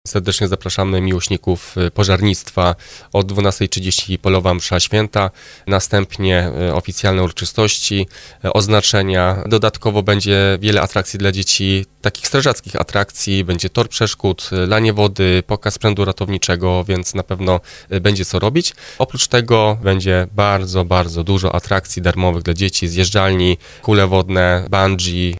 – wymieniał wójt gminy Gręboszów Krzysztof Gil.